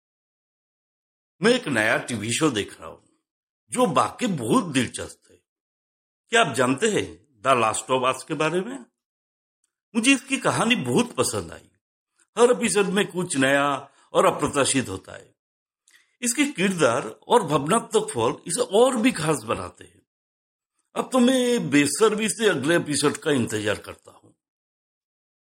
TV/Radio Promo – Hindi (High Energy)